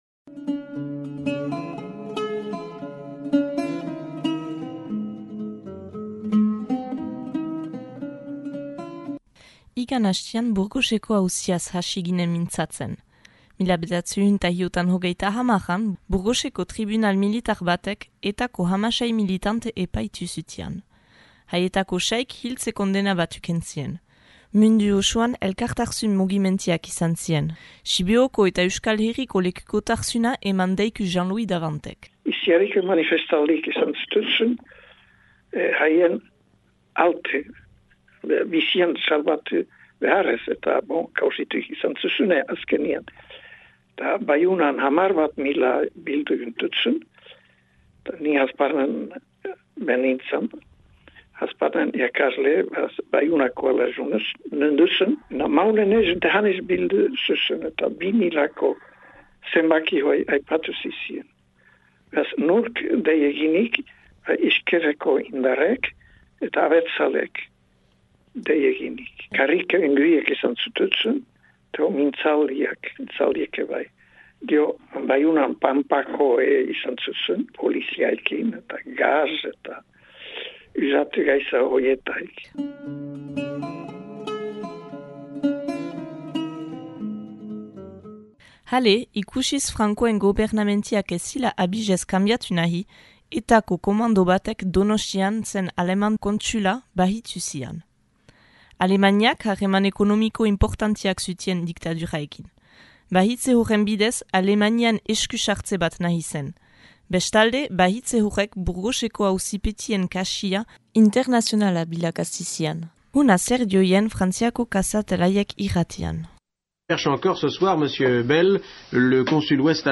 frantses irratiko artxiboekin